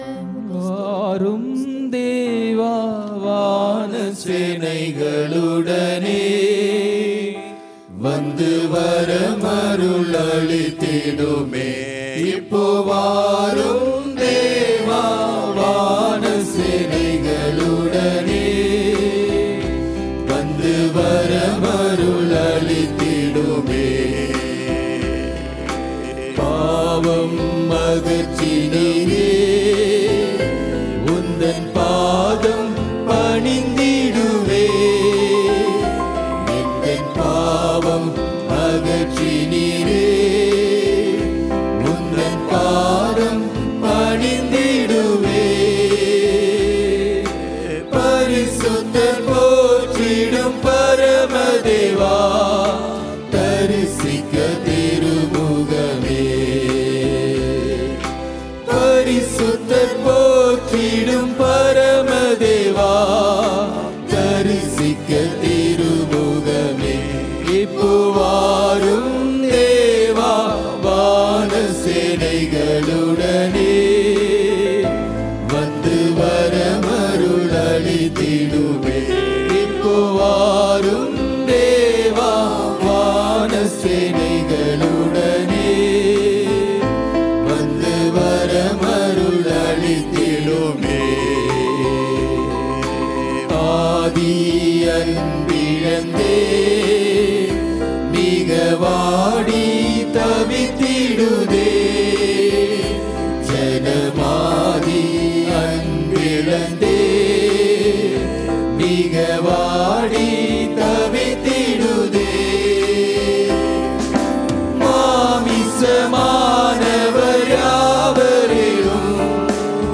12 June 2022 Sunday Morning Service – Christ King Faith Mission